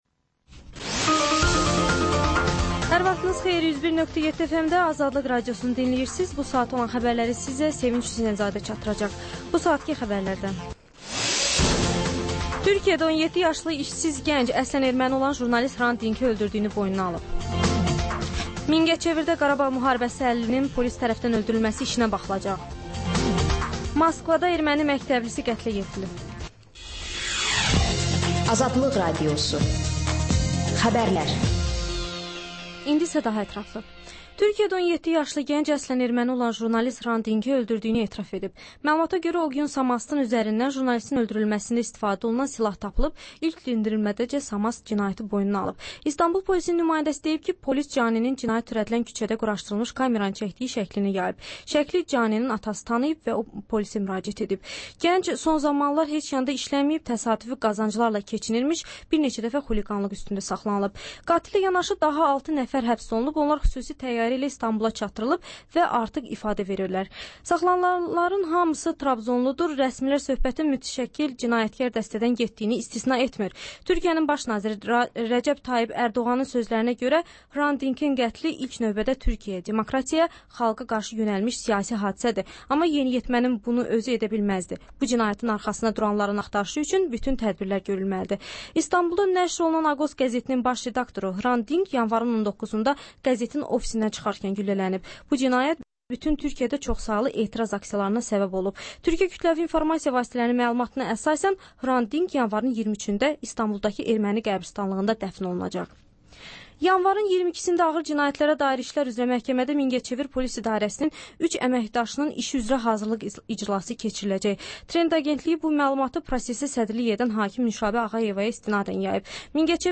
Xəbərlər, reportajlar, müsahibələr.